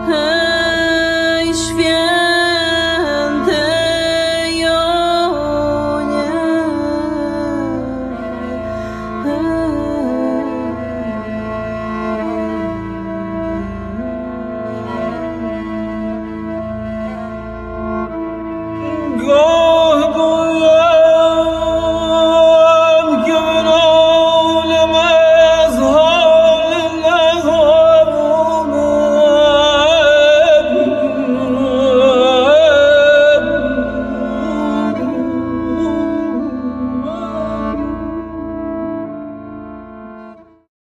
śpiew, padudla (fidel płocka), suka biłgorajska
skrzypce żłobione
cymbały
śpiew alikwotowy, bas
bębny
kemancze
instrumenty klawiszowe